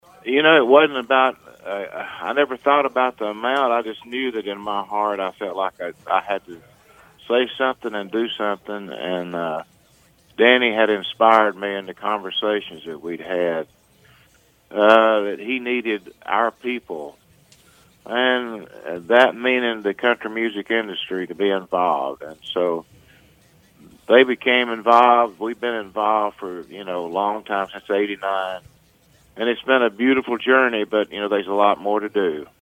There were several highlights throughout the day Wednesday including a call from Randy Owen from Country Group “Alabama”. Randy was responsible for starting the Radio-Thons across the country more than 30 years ago and talked to us about why it means so much to him